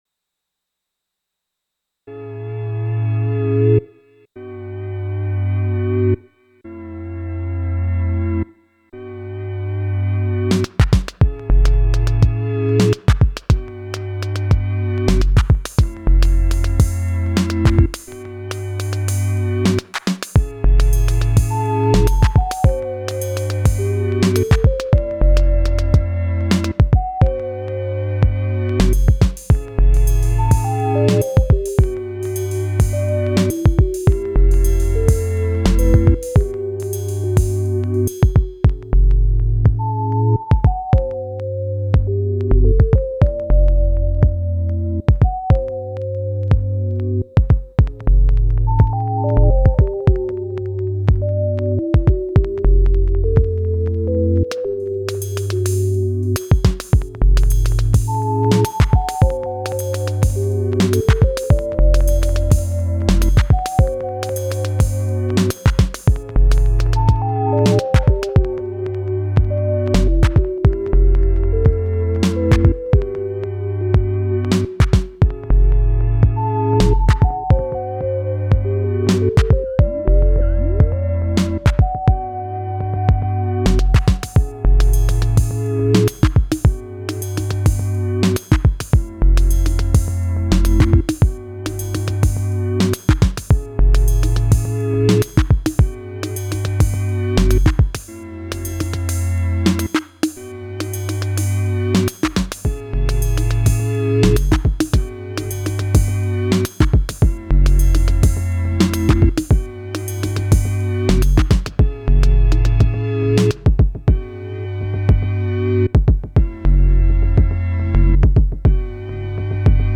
A lot of people say that Rytm doesn’t have that bite, that beat, that sting, but mind you–it’s razor sharp when you need it in your hour of darkness:
No samples except for the obvious drop from some old movie you might know.